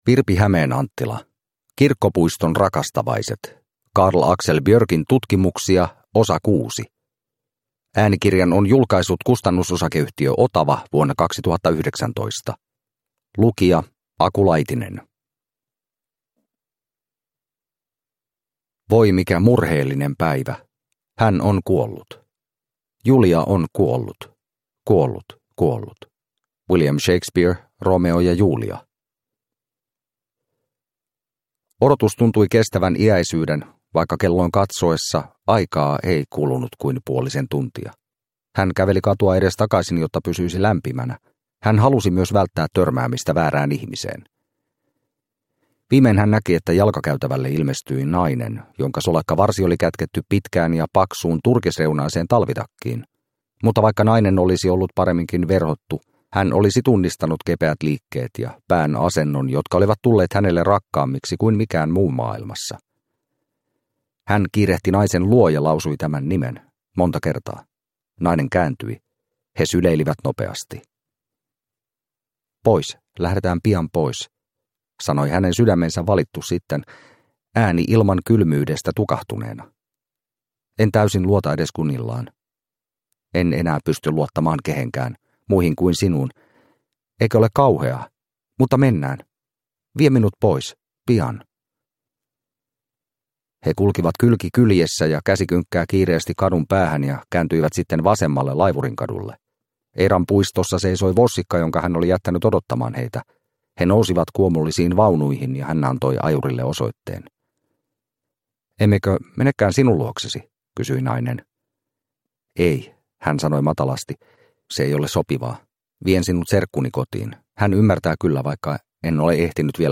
Kirkkopuiston rakastavaiset – Ljudbok – Laddas ner